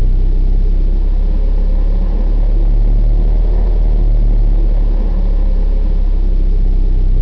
rg_hum.ogg